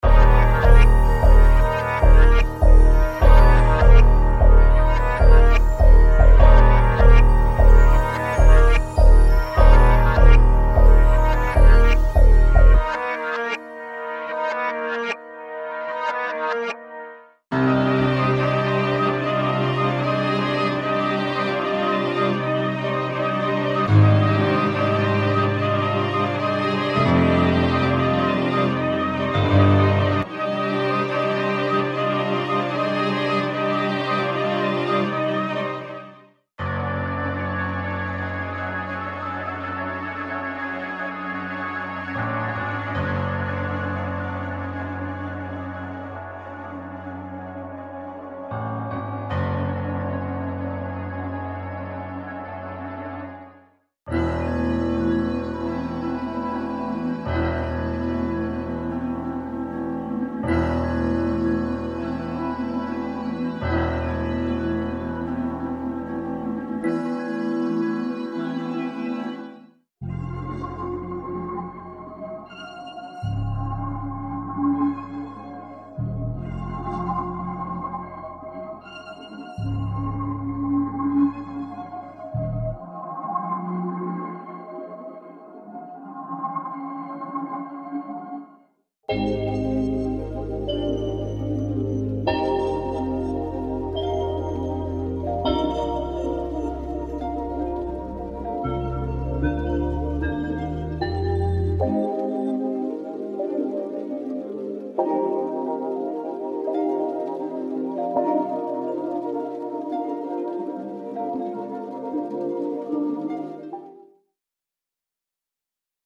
黑暗陷阱灵感样本包